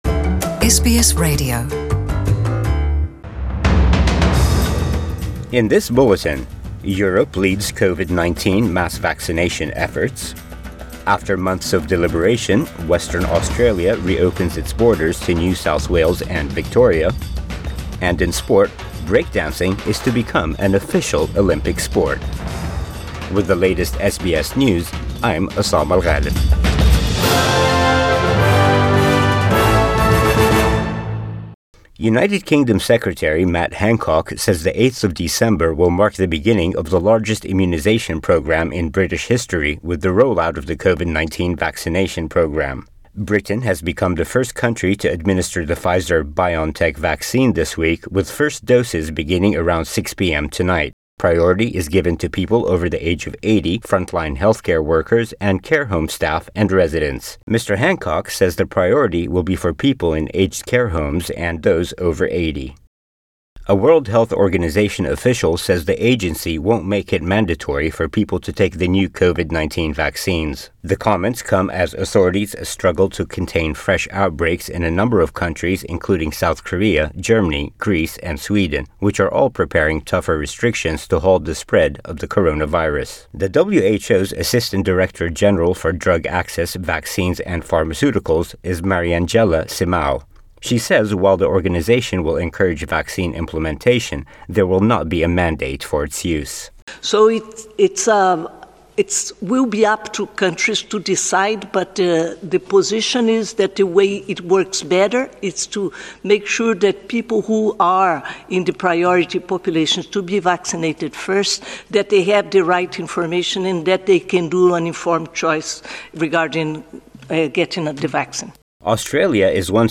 Midday Bulletin 8 December 2020